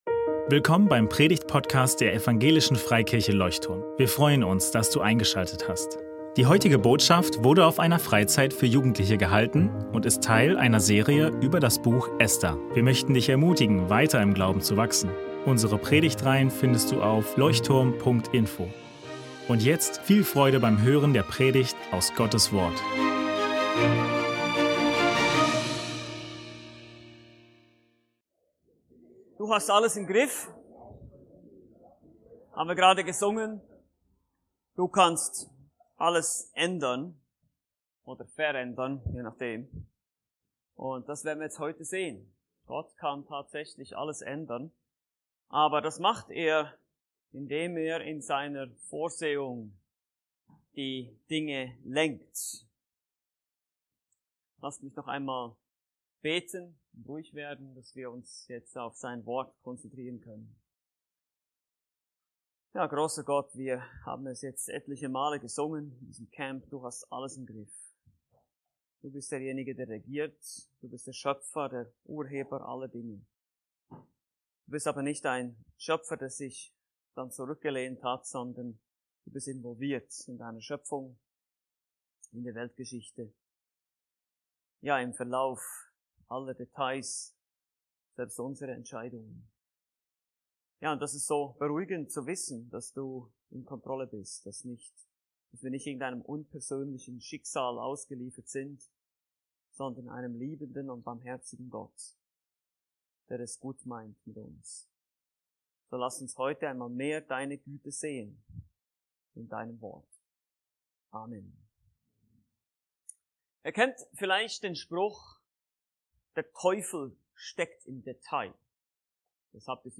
Das Blatt wendet sich ~ Leuchtturm Predigtpodcast Podcast